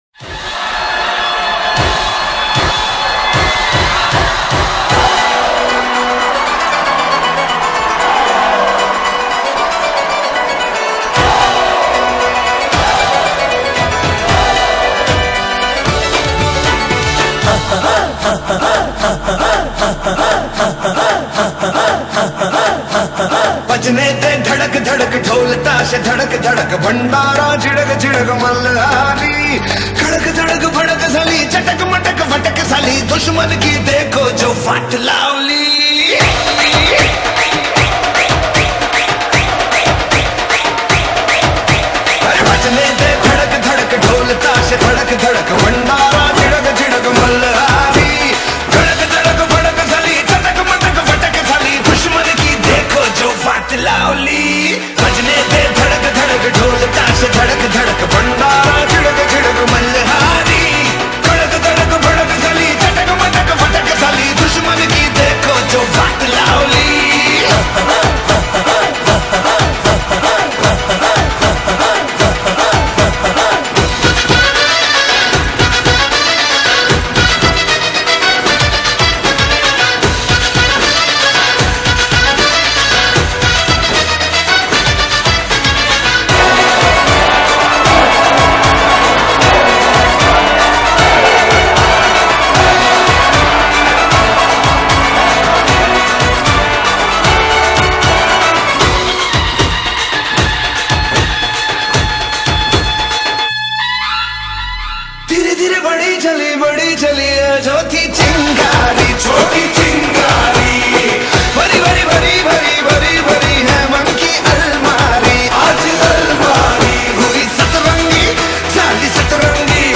Carpeta: musica hindu mp3
La cancion entusiasta india más bella